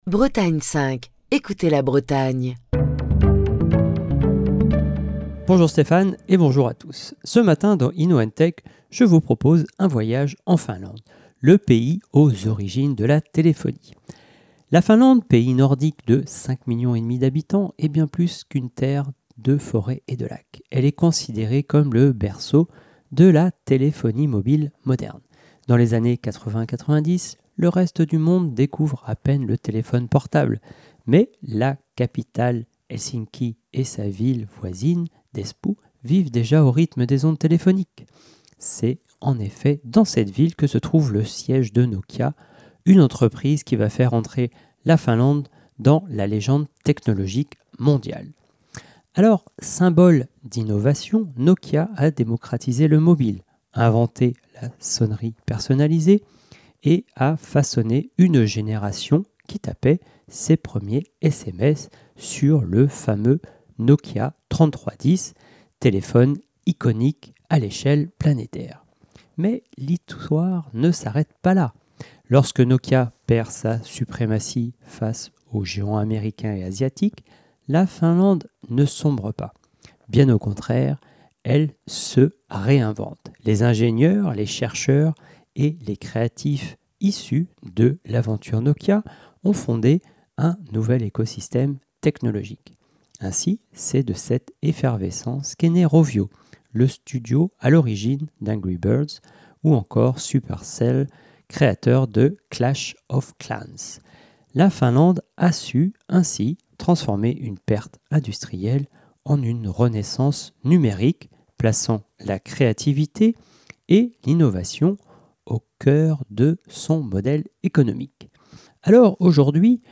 Chronique du 27 octobre 2025.